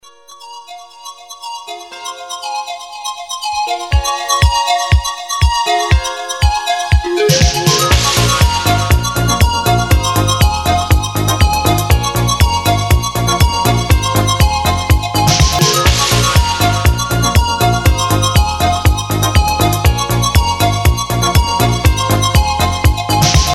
• Качество: 192, Stereo
красивые
инструментальные
ретро